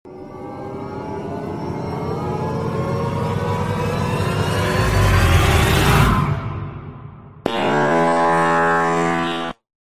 Dramatic Fart Sound Effect Free Download
Dramatic Fart